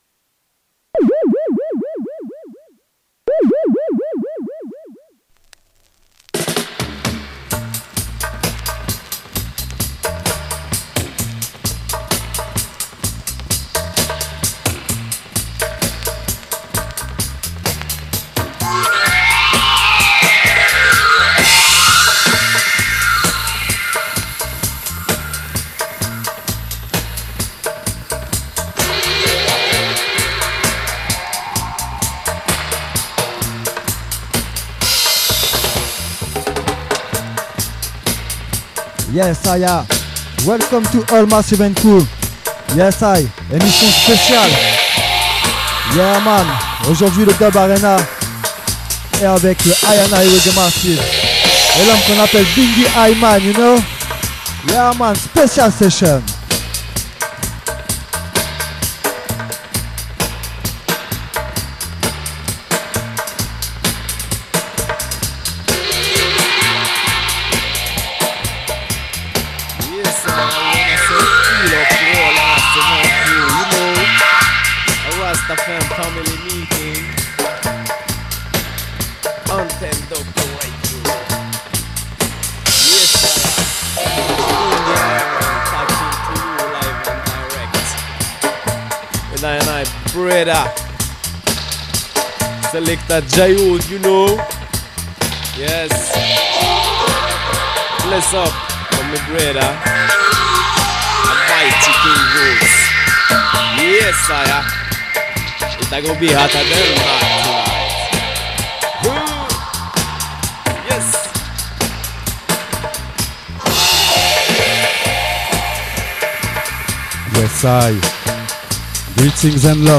Strictly Vinyl Selections...